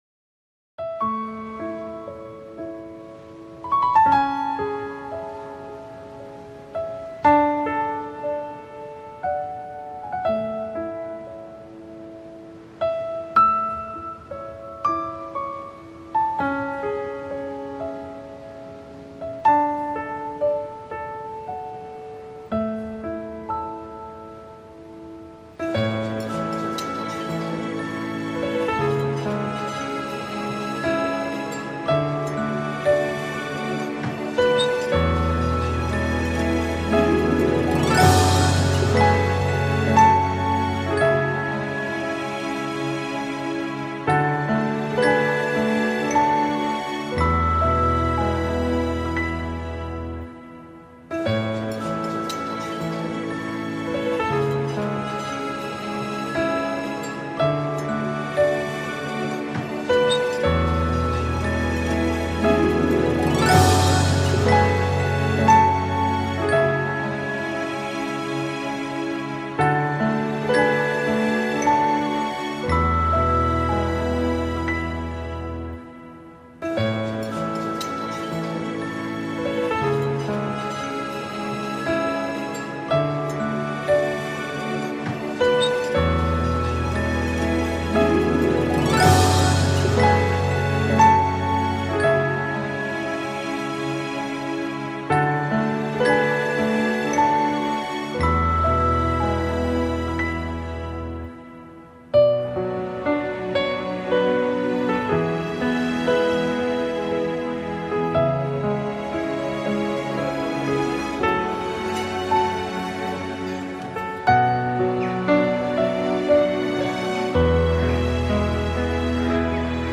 mutlu huzurlu rahatlatıcı fon müziği.